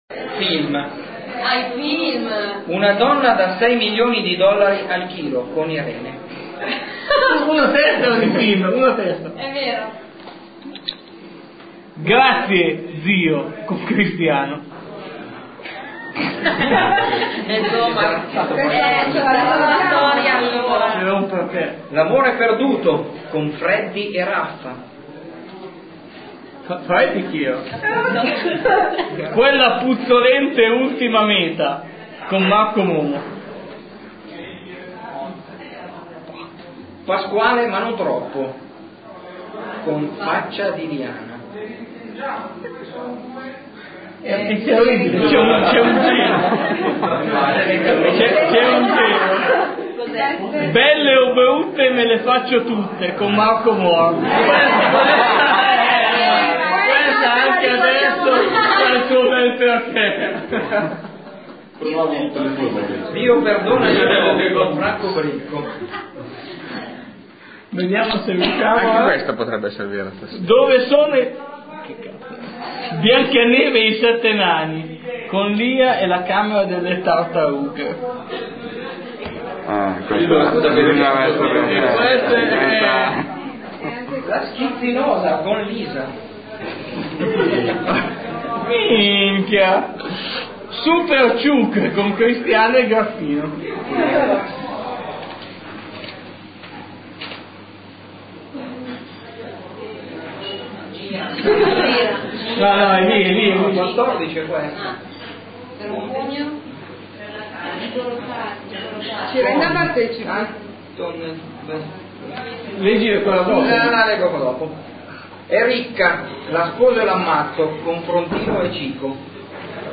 Scarica le testimonianze della serata....
gof2003-lettura-film.mp3